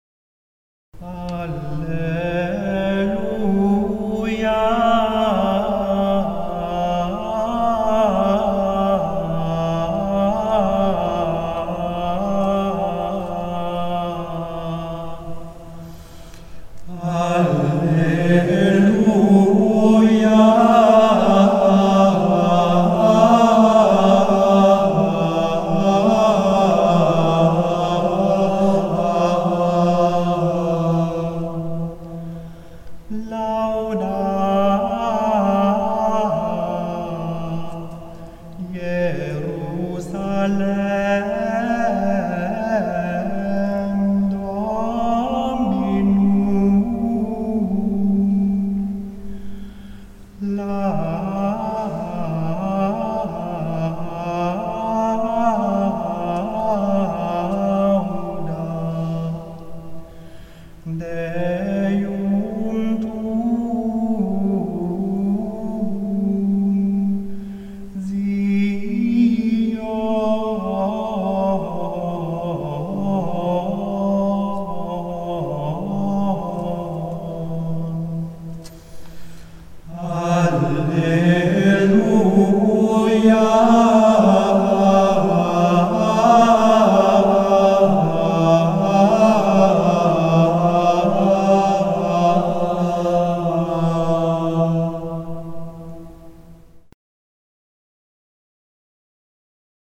Liturgischer Singkreis Jena - gregorianische Choralschola Liturgischer Singkreis Jena - gregorianische Choralschola